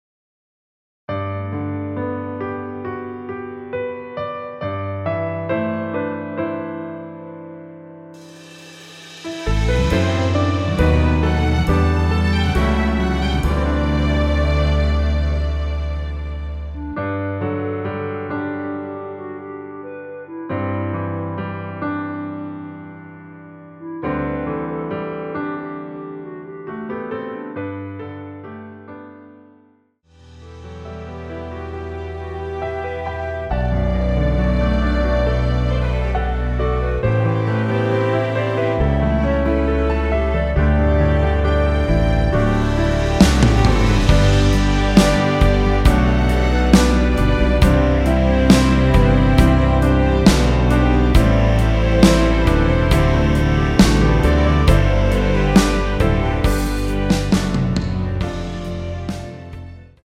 원키에서(-4)내린 멜로디 포함된 MR 입니다.(미리듣기 참조)
앞부분30초, 뒷부분30초씩 편집해서 올려 드리고 있습니다.
중간에 음이 끈어지고 다시 나오는 이유는